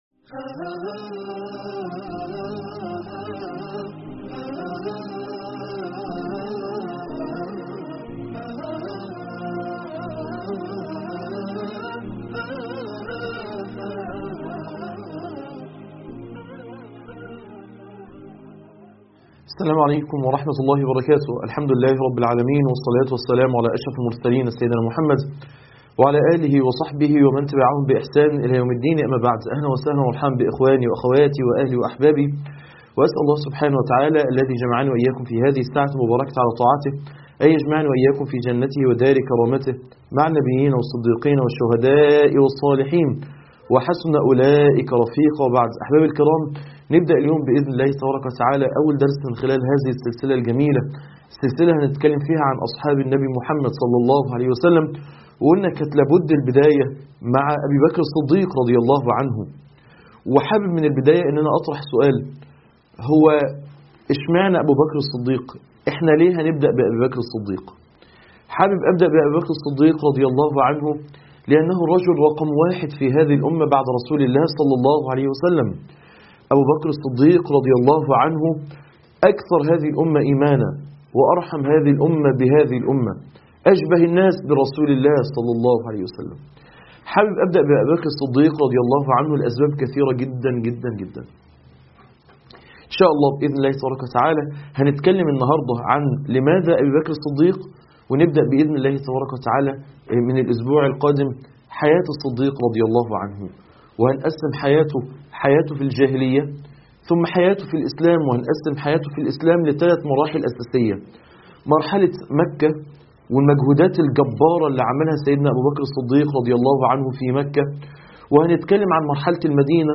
لماذا الصديق ؟ محاضرة غرفة الهداية الدعوية